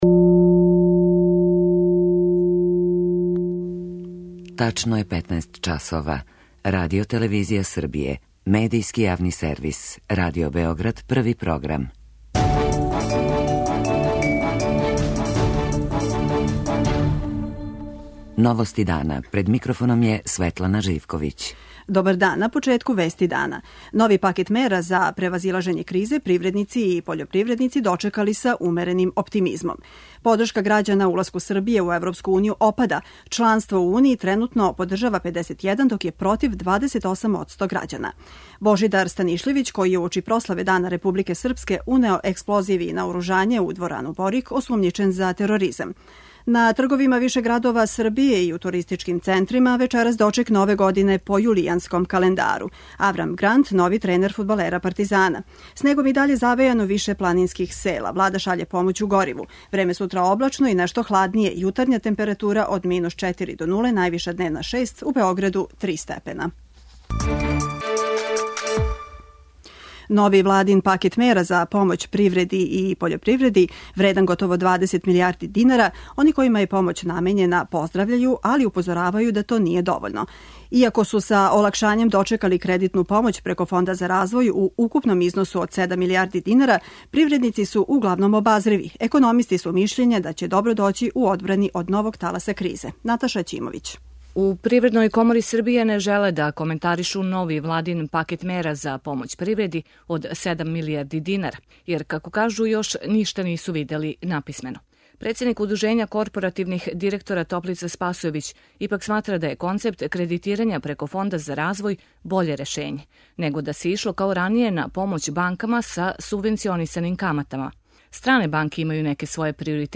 Чућете како су ове мере оценили привредници и пољопривредници.
преузми : 15.76 MB Новости дана Autor: Радио Београд 1 “Новости дана”, централна информативна емисија Првог програма Радио Београда емитује се од јесени 1958. године.